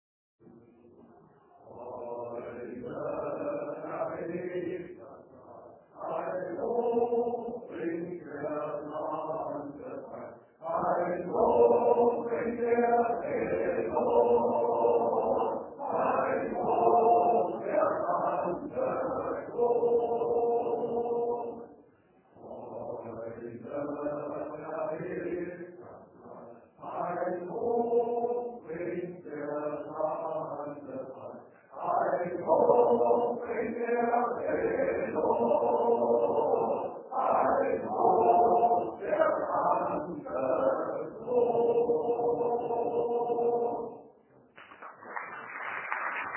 Die Gründungsfeierstunde wurde im Saal der Gaststätte Schlegelsberg, dem wöchentlichen Probenort durchgeführt und fand regen Zuspruch.
Es folgten mehrere Vorträge des Chores.
Hoch.dem.Chor.mp3